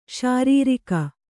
♪ śarīrika